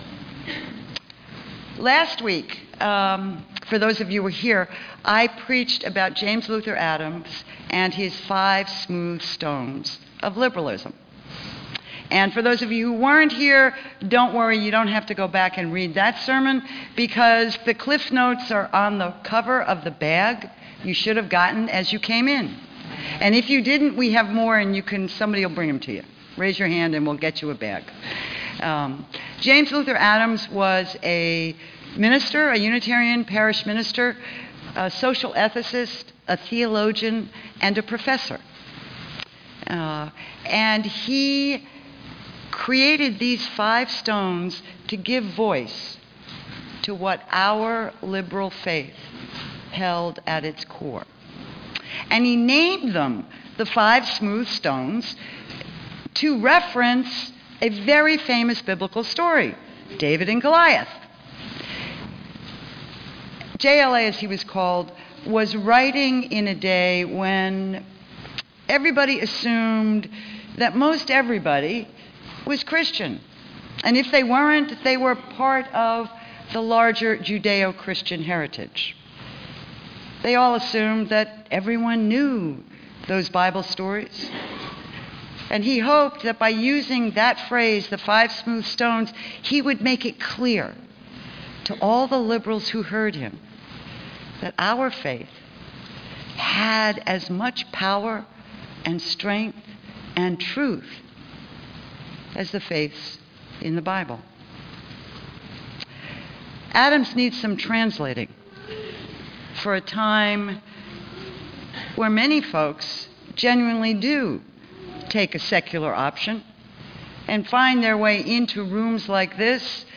by | Aug 25, 2013 | Recorded Sermons | 0 comments